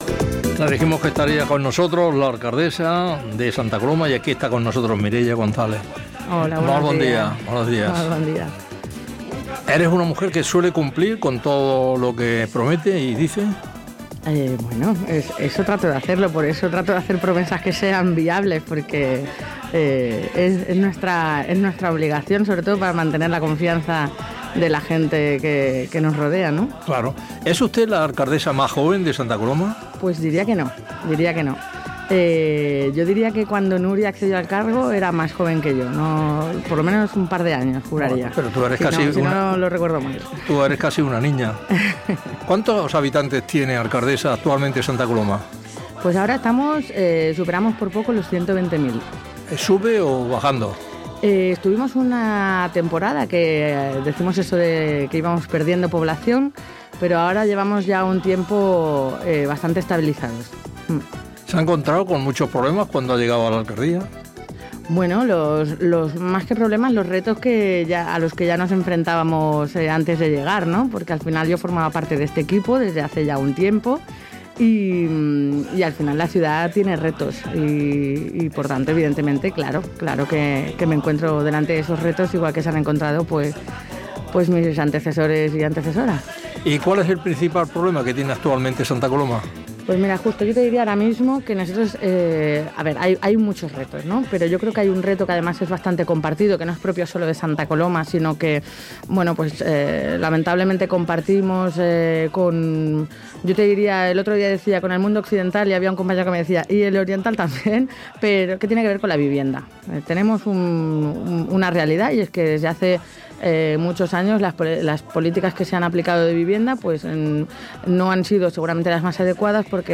¡Disfruta de la entrevista de Justo Molinero a Mireia González, la alcaldesa de Sta Coloma de Gramenet en Radio TeleTaxi!